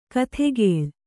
♪ kathegēḷ